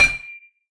metinstone_insert.wav